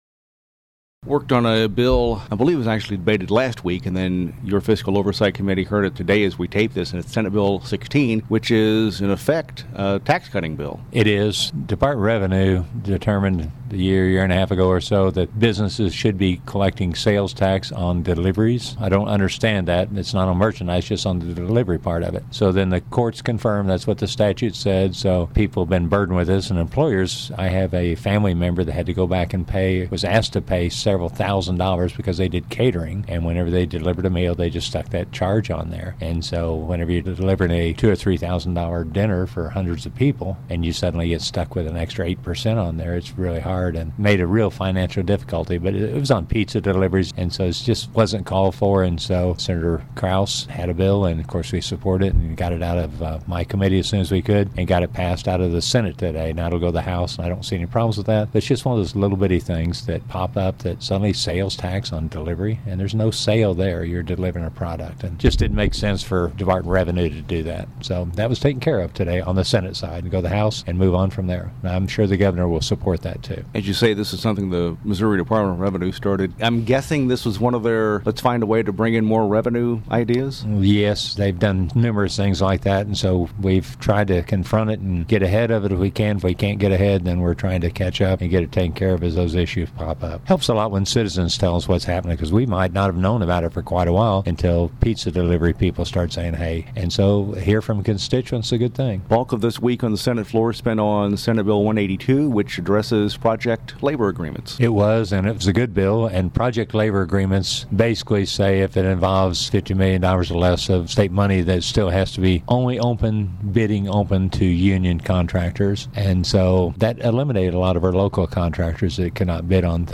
JEFFERSON CITY — State Sen. Mike Cunningham, R-Rogersville, discusses Senate Bill 16, legislation that seeks to exempt delivery charges from sales and use taxes.